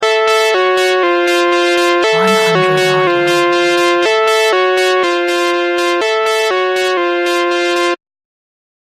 It is a sound effect that creates a tense and hostile atmosphere. Ideal for acclimating casual fights in your game or to use it in your songs.